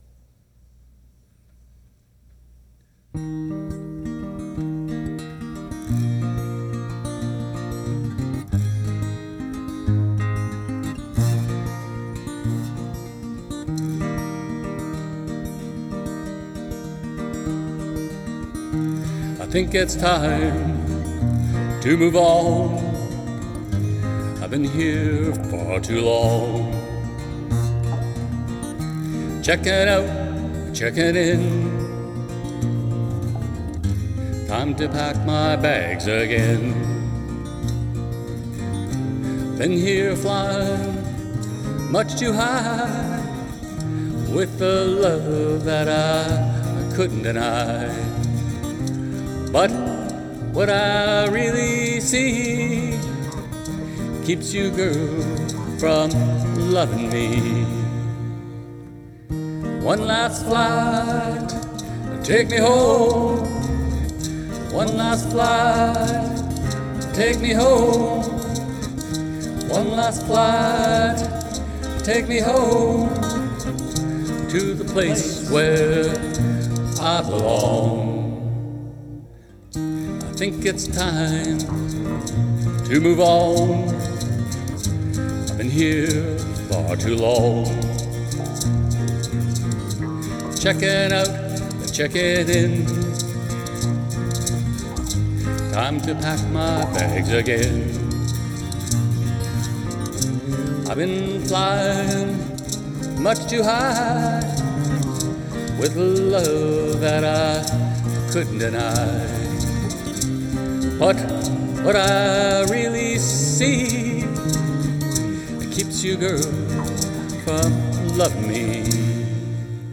Guitar and vocals sound great.